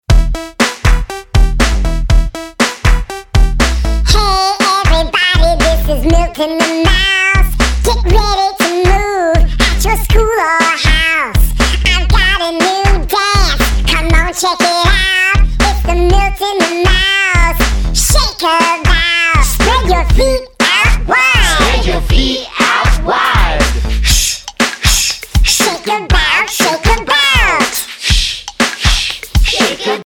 fun, upbeat, dance, exercise and creative movement songs